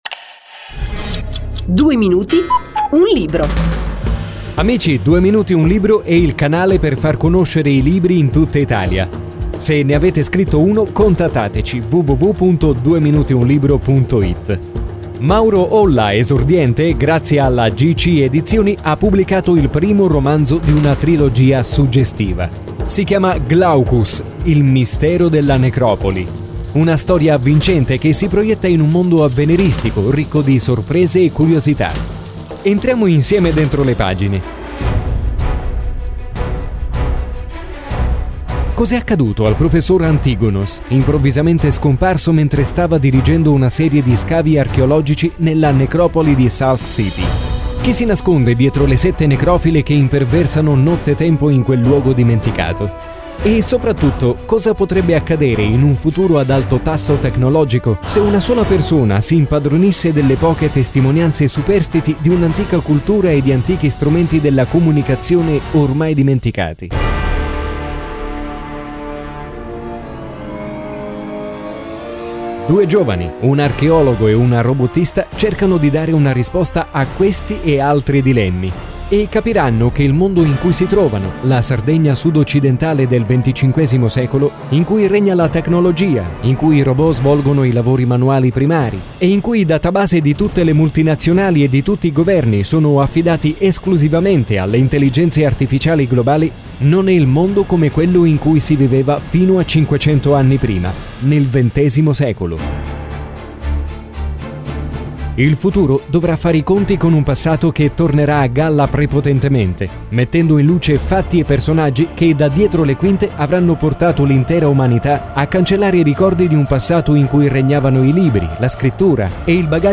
Recensione audio di Glaucus – Il mistero della Necropoli trasmessa nelle radio.